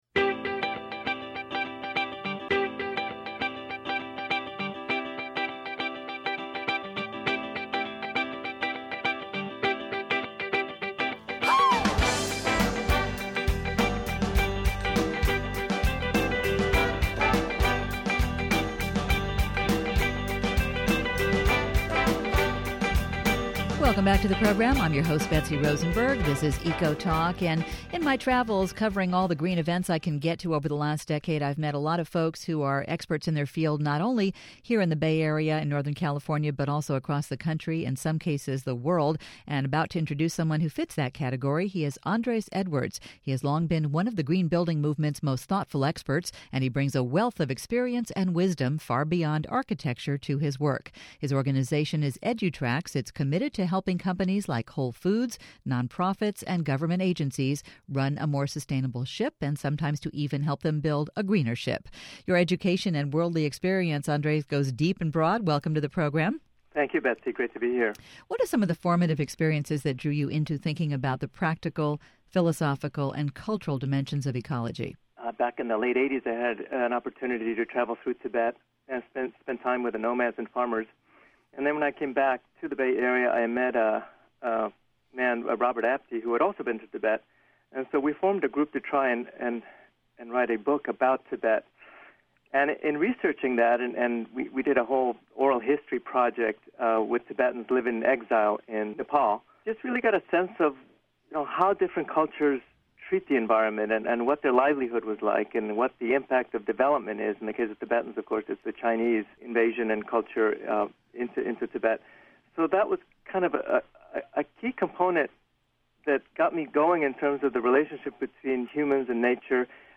Recorded Interviews